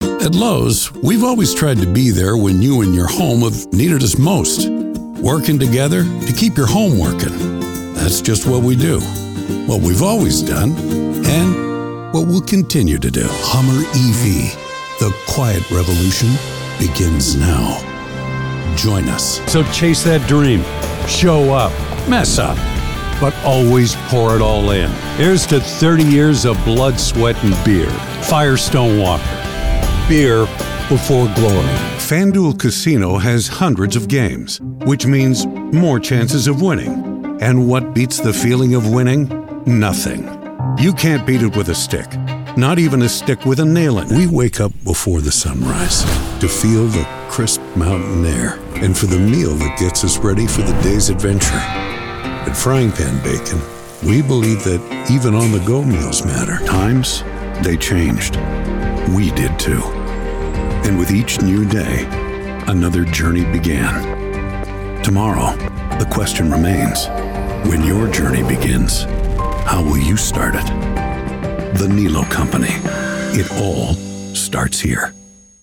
Anglais (canadien)
Commandant
Chaleureux
Amical